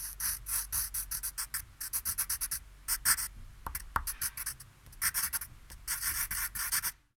Sonidos: Acciones humanas
Sonidos: Oficina